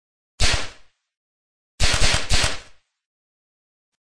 Descarga de Sonidos mp3 Gratis: disparo 12.